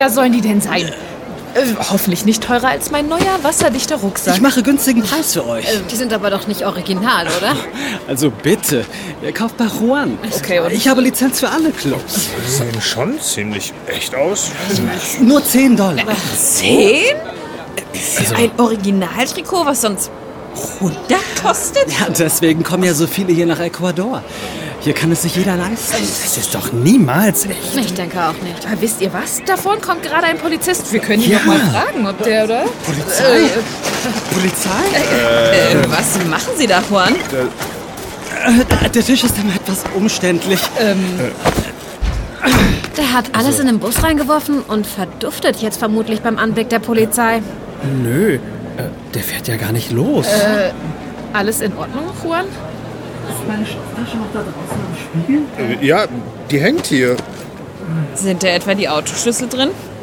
Genre : Hörspiel